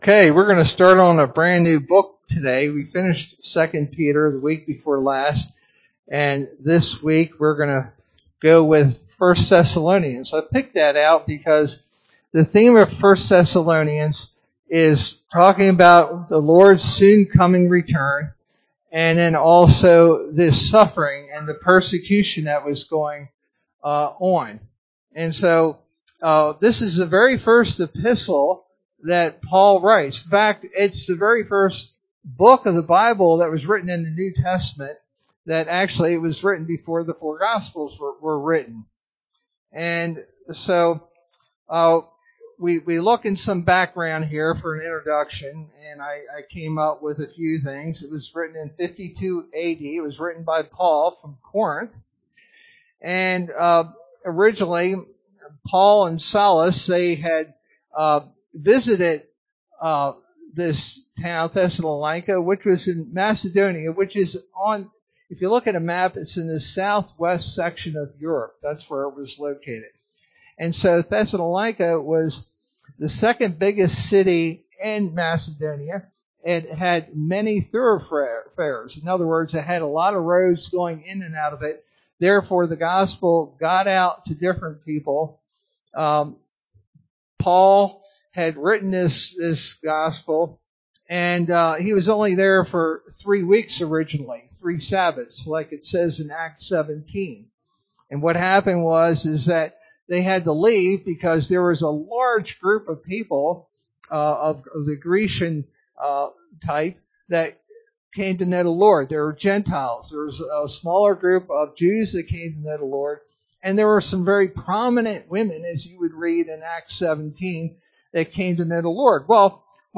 Sermon verse: 1 Thessalonians 1:1-10